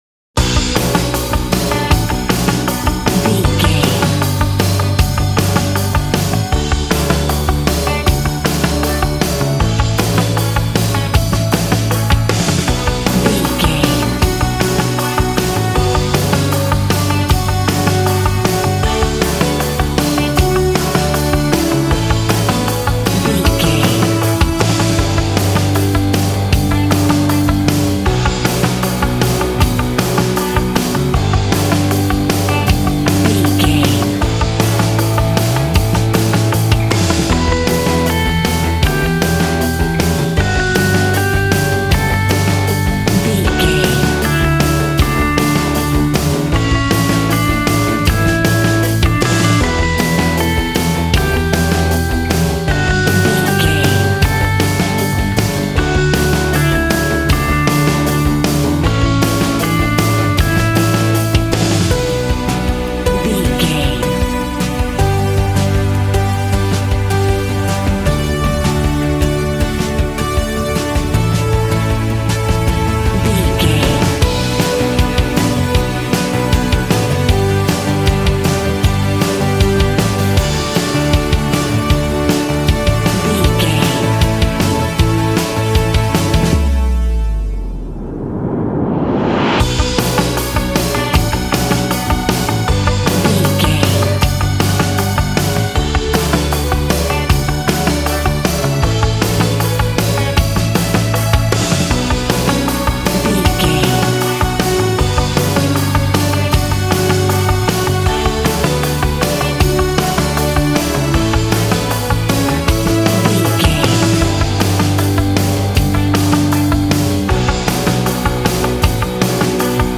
Ionian/Major
groovy
powerful
fun
organ
drums
bass guitar
electric guitar
piano